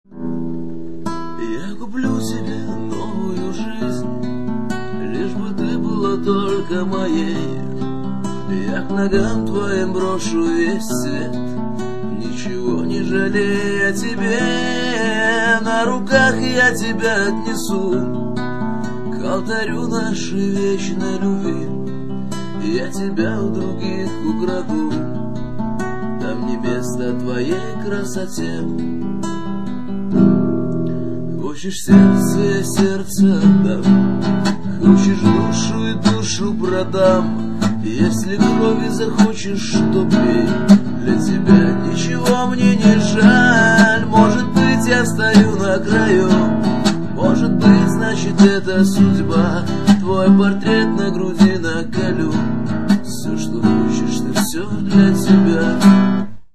гитара
дворовые
под гитару